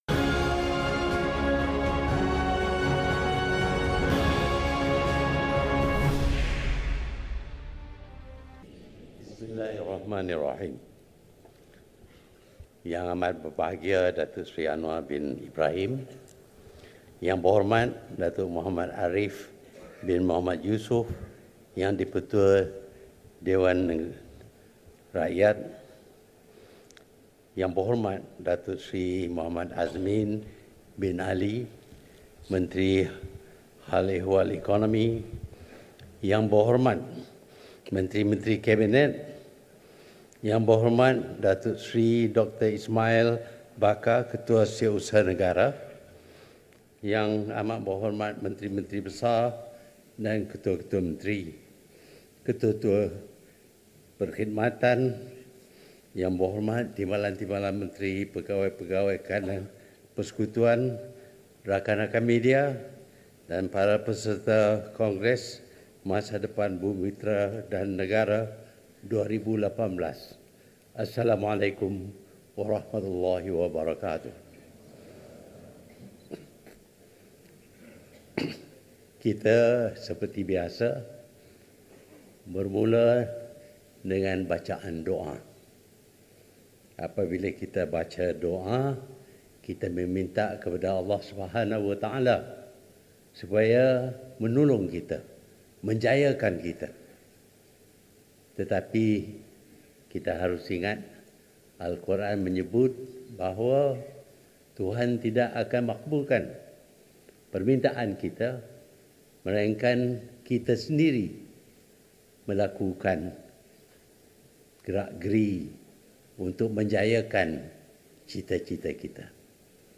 Ikuti ucaptama perasmian Kongres Masa Depan Bumiputera dan Negara 2018 oleh Perdana Menteri Tun Dr Mahathir Mohamad yang berlangsung di Putrajaya.